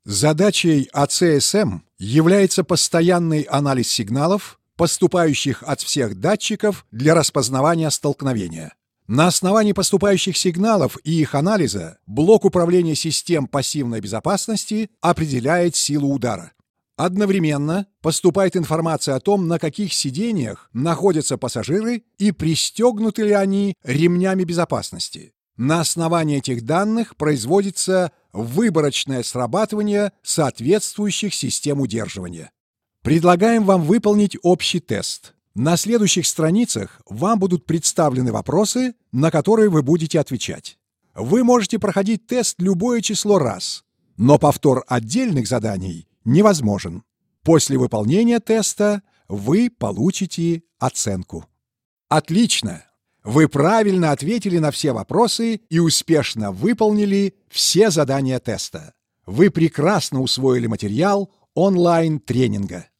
Warmer samtiger Bariton
Sprechprobe: Sonstiges (Muttersprache):